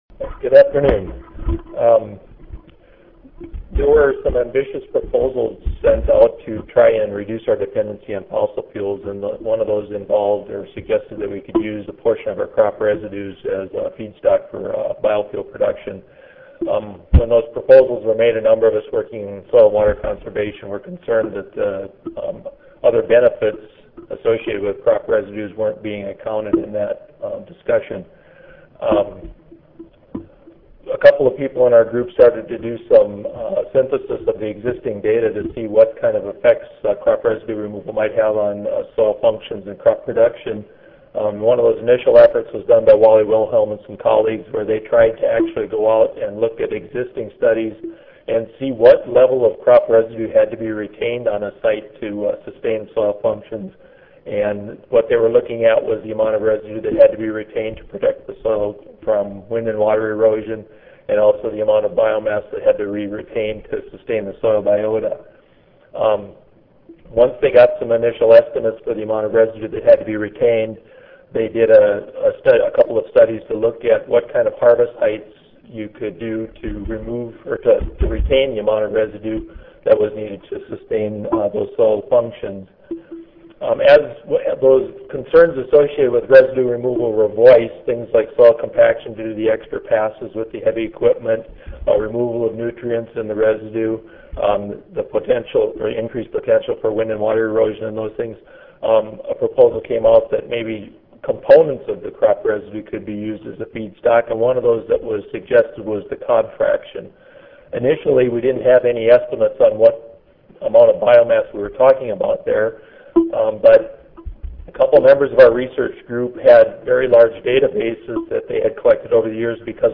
NE Audio File Recorded presentation The cob fraction of corn